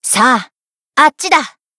BA_V_Utaha_Cheerleader_Battle_Shout_2.ogg